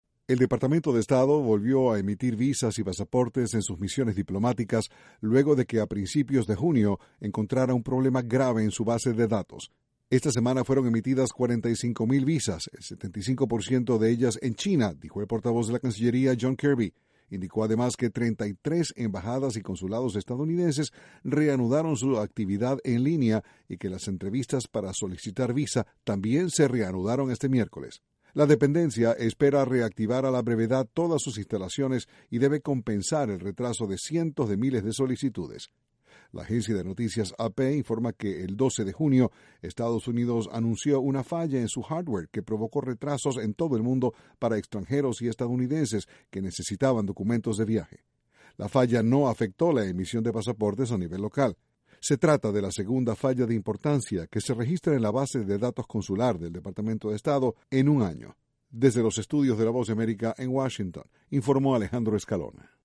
Estados Unidos reanudo la emision de visas luego de superar problemas en su base consular de datos. Desde la Voz de America, Washington